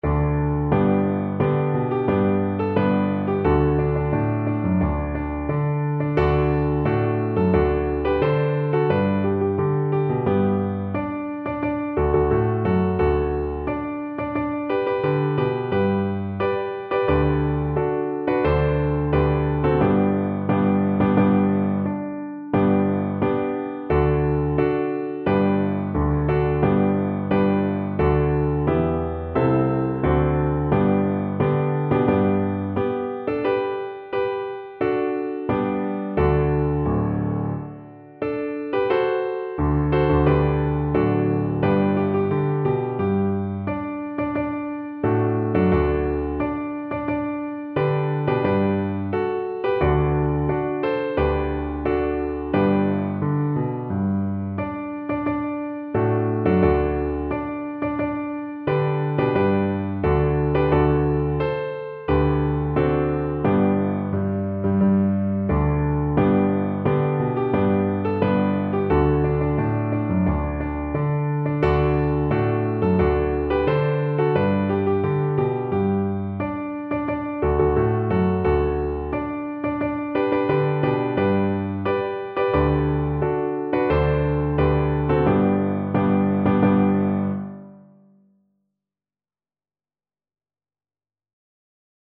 4/4 (View more 4/4 Music)
D5-D6
~ = 100 Moderato, maestoso =c.88